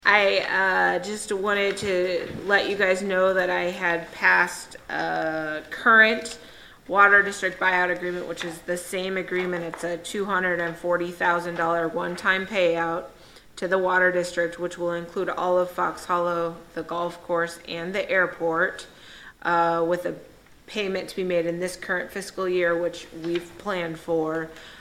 The Boonville City Council received a report from City Administrator Kate Fjell about a water district buyout agreement during its meeting on May 16.
Fjell reported the details of the agreement to the council.